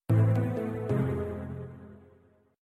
level_fail.mp3